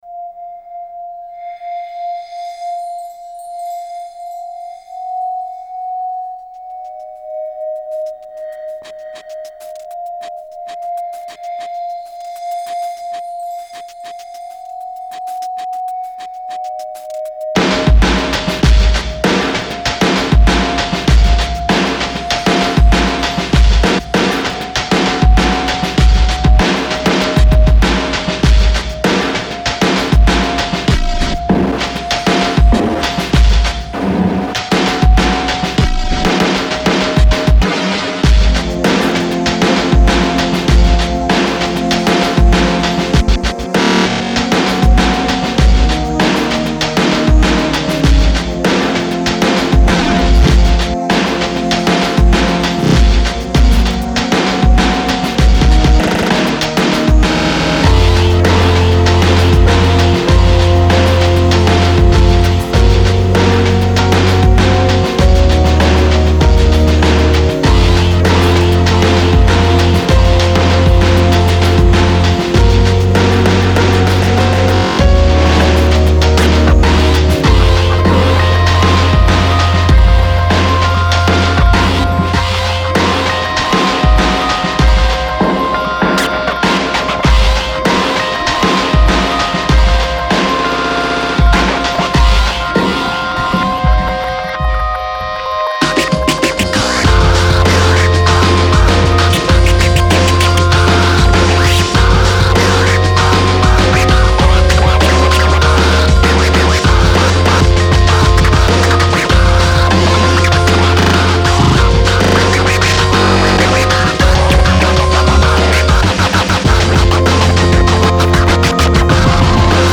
Genre : Rock, Alternative Rock